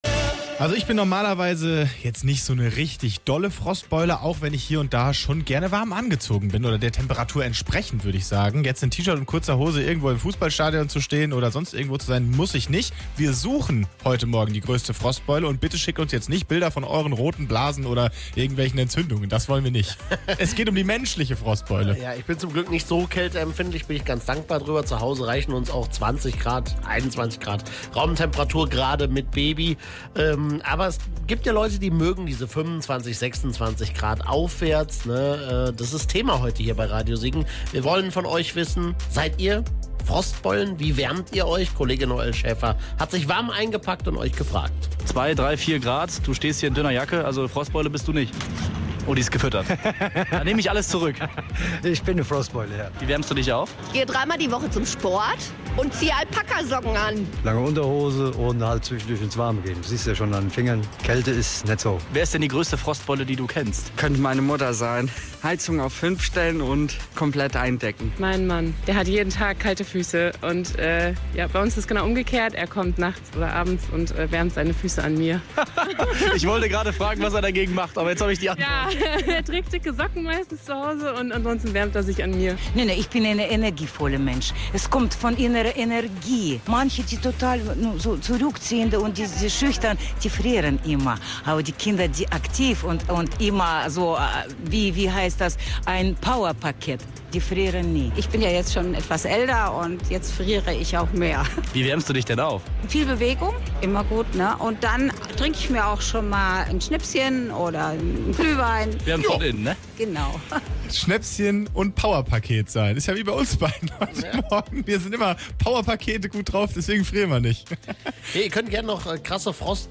Seid ihr Frostbeulen? Stimmen aus dem Radio Siegen Land
Frostbeulen Umfrage